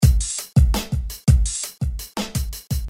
描述：果味循环
Tag: 84 bpm Rap Loops Drum Loops 984.61 KB wav Key : Unknown